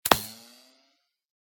whine01.ogg